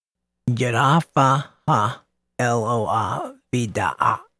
yud-heh-vahv-heh el-oh-ah v'dah-aht
yud-heh-vahv-heh-elo-oh-ah-vdah-aht.mp3